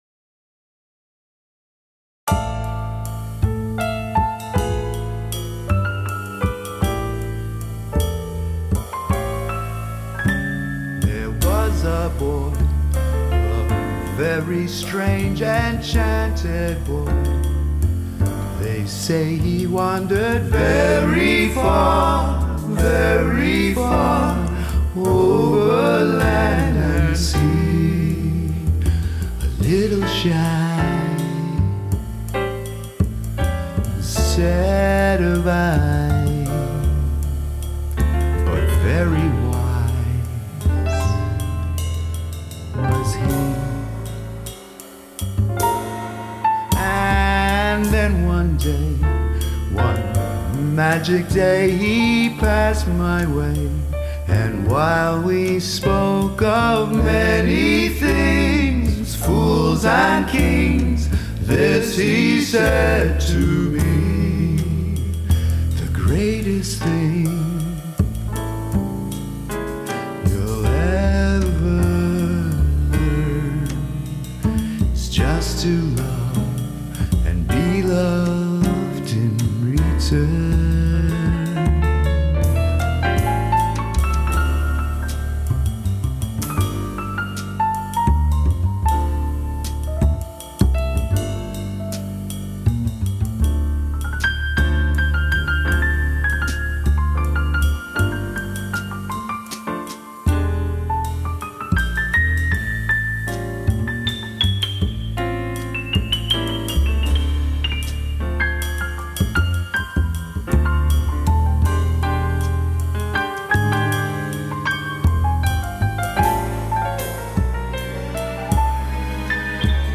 Piano
jazz standards